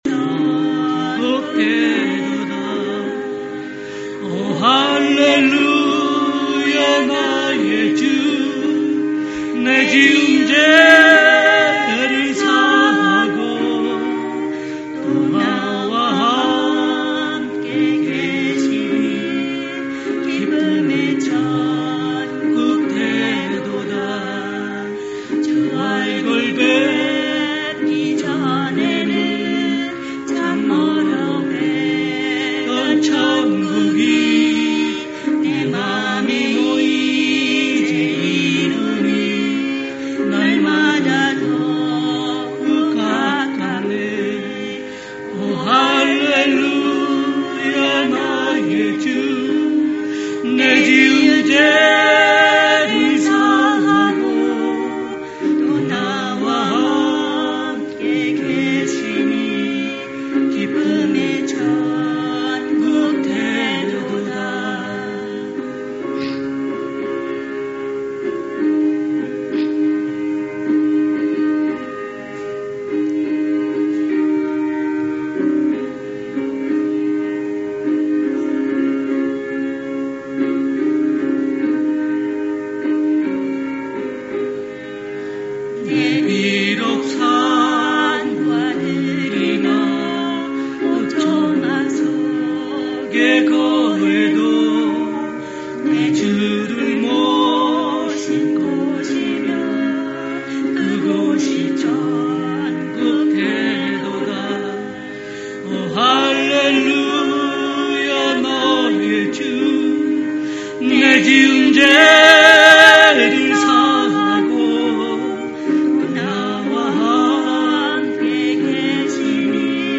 Special Music
family praise 02